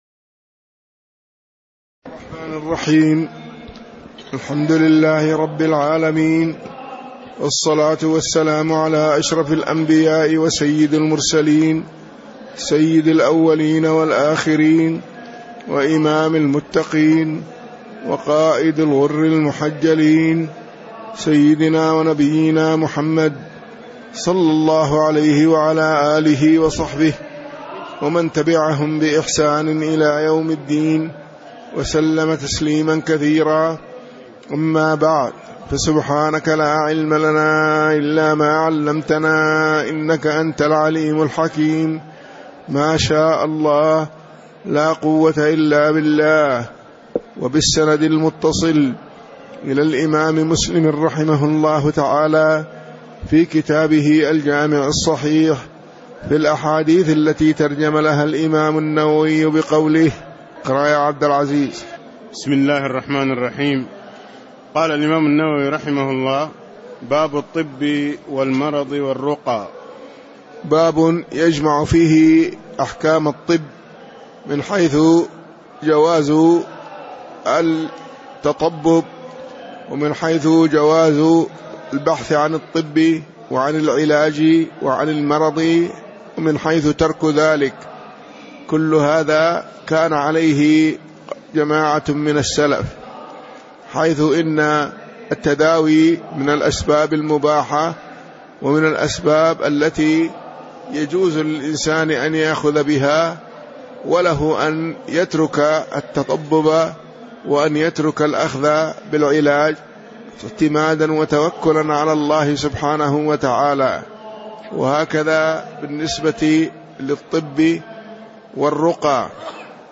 تاريخ النشر ٢١ محرم ١٤٣٧ هـ المكان: المسجد النبوي الشيخ